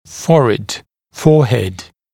[‘fɔrɪd] [‘fɔːhed][‘форид ], [‘фо:хэд]лоб